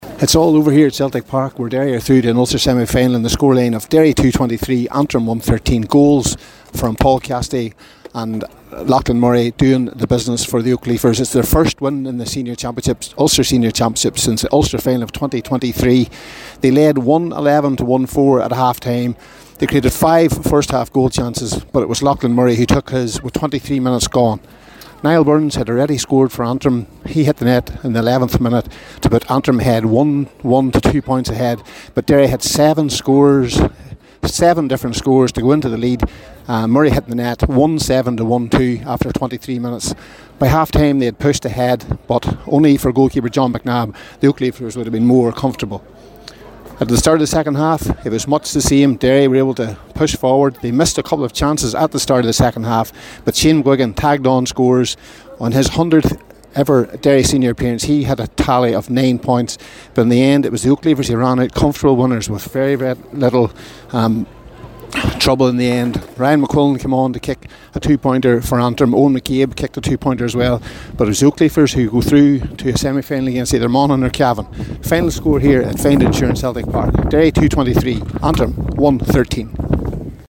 the full time report